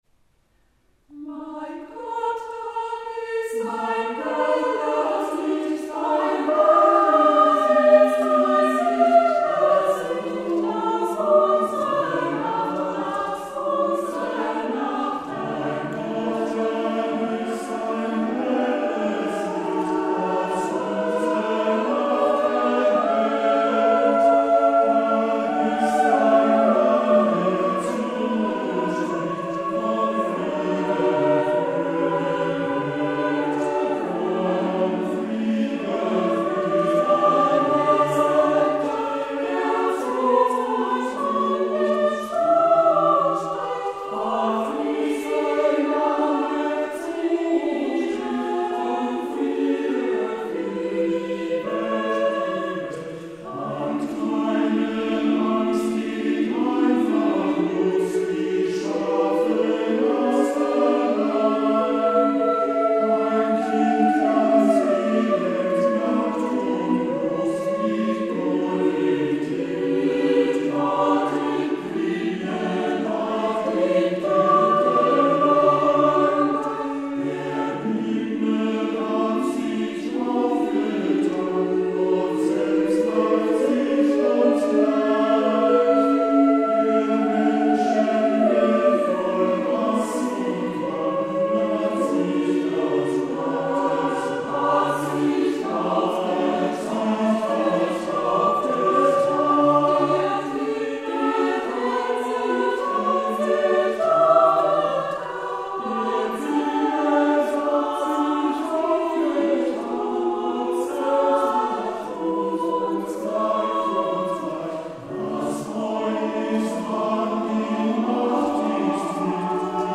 Konzertmitschnitt vom 29.12.2017, Kulturkirche Epiphanias Mannheim